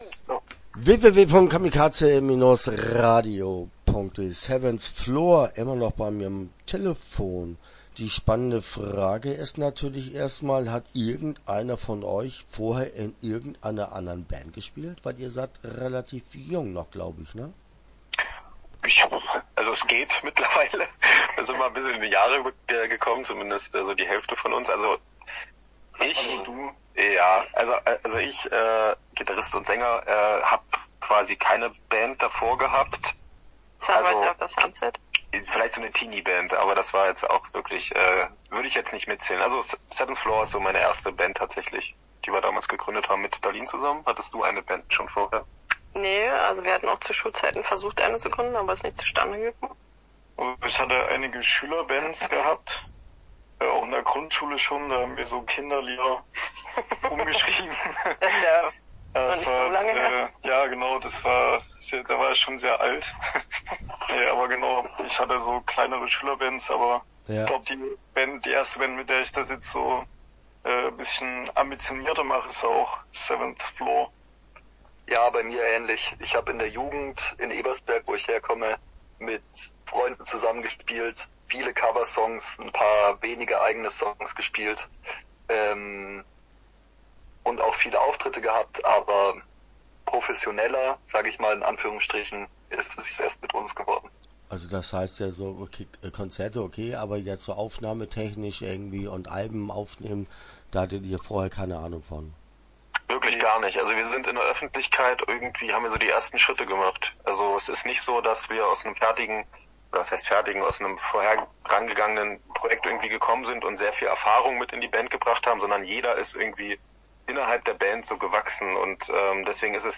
Seventh Floor - Interview Teil 1 (10:37)